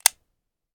weap_mike9a3_disconnector_plr_01.ogg